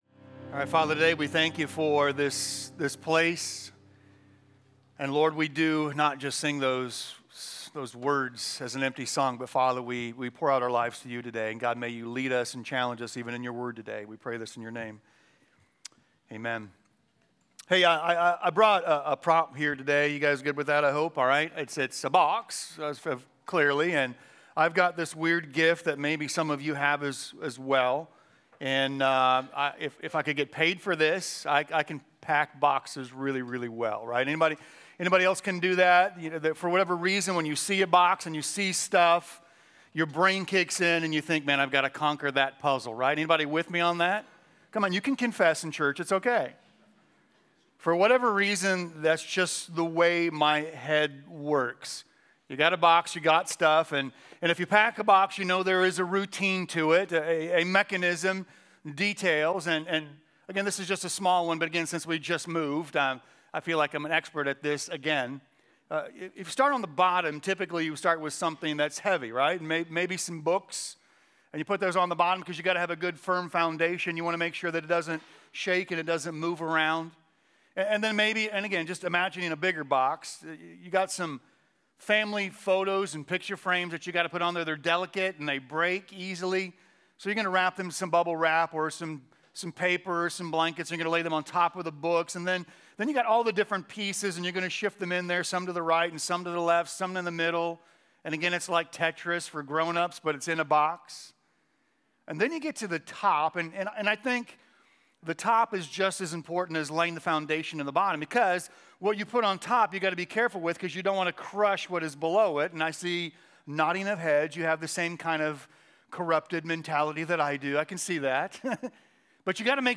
Sermons – Commonway Church